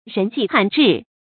人跡罕至 注音： ㄖㄣˊ ㄐㄧˋ ㄏㄢˇ ㄓㄧˋ 讀音讀法： 意思解釋： 罕：少。